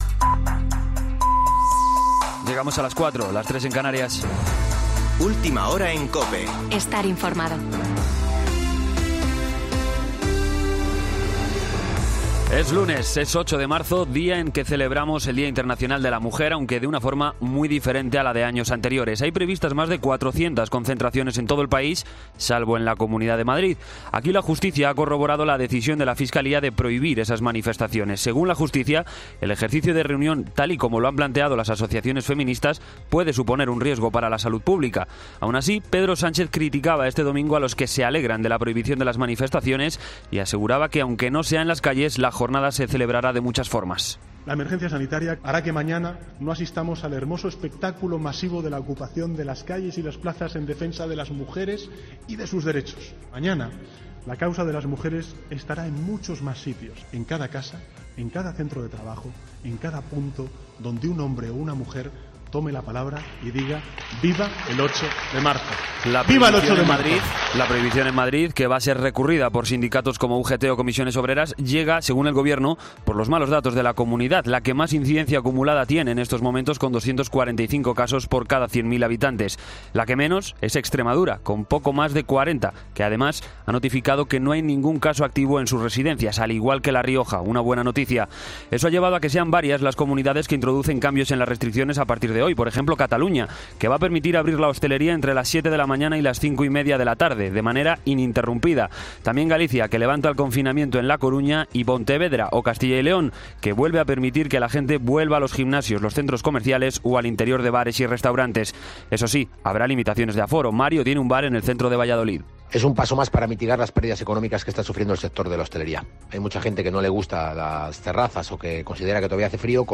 Boletín de noticias COPE del 8 de marzo de 2021 a las 04.00 horas